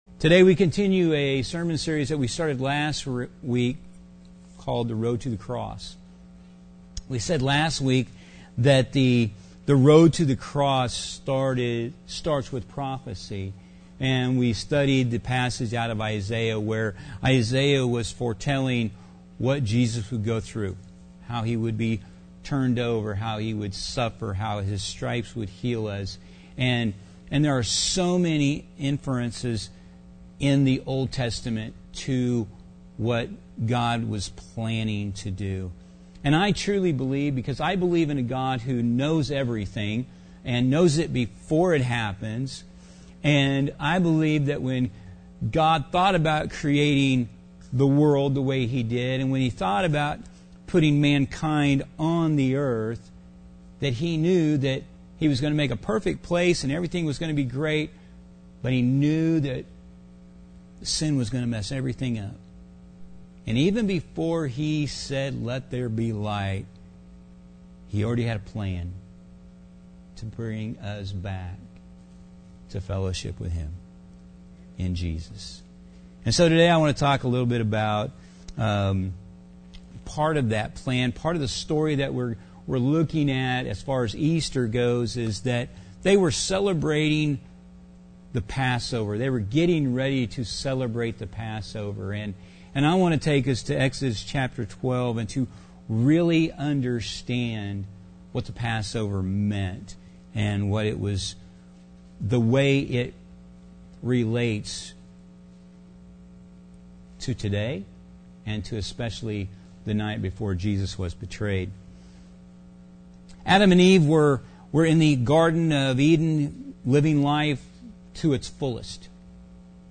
Sermon: Road to the Cross CrossRoads Topeka – CrossRoads Wesleyan Church